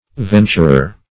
Venturer \Ven"tur*er\, n.